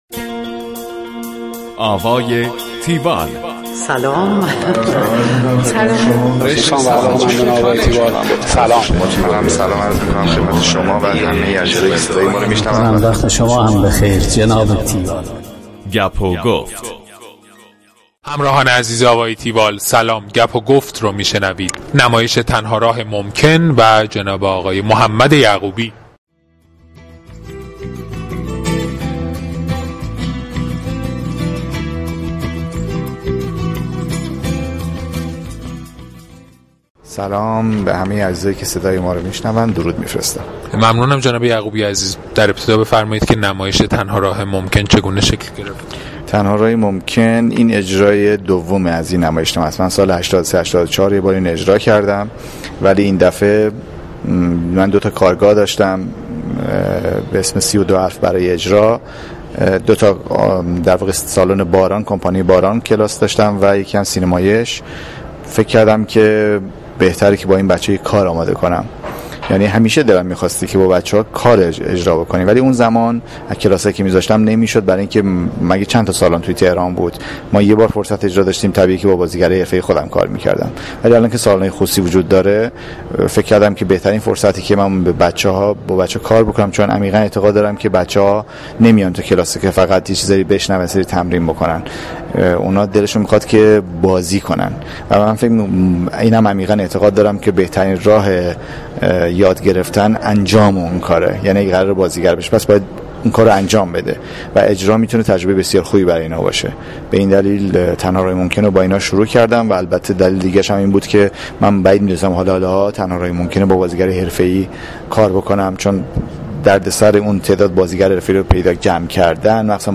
گفتگوی تیوال با محمد یعقوبی
tiwall-interview-mohamadyaghoobi.mp3